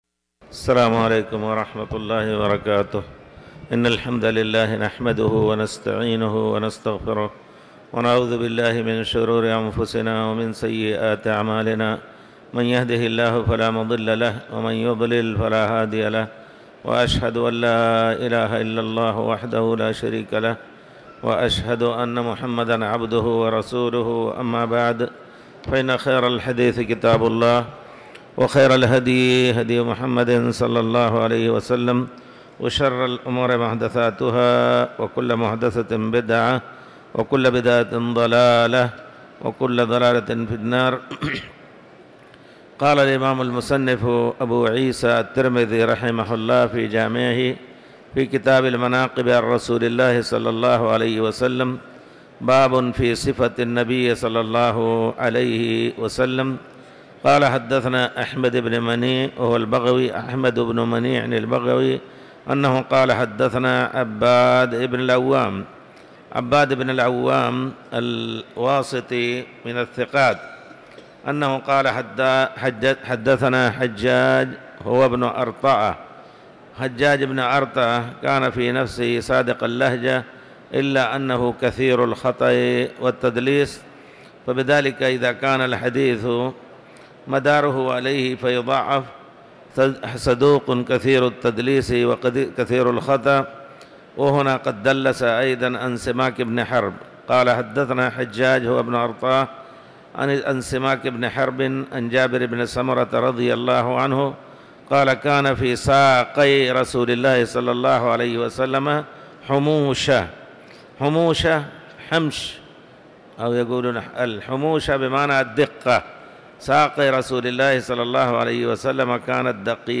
تاريخ النشر ٦ رجب ١٤٤٠ هـ المكان: المسجد الحرام الشيخ